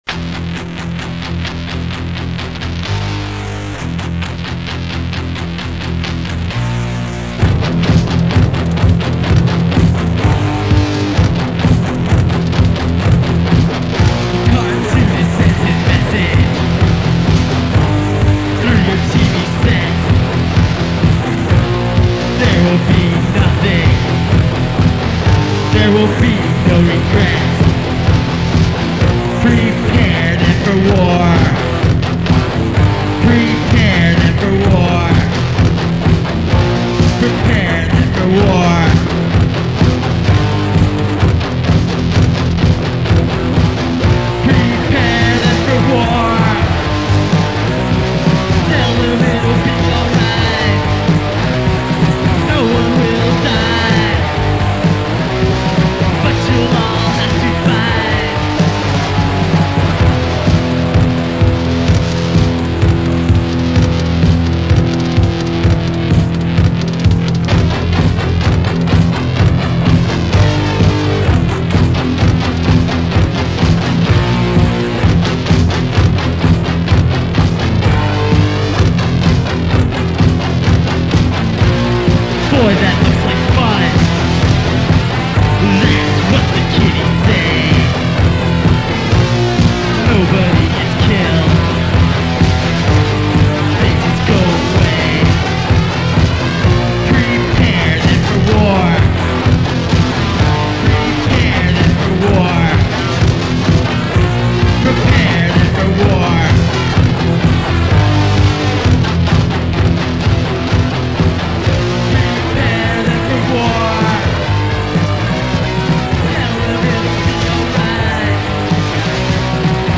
Early Lehigh Valley punk rock (1980s)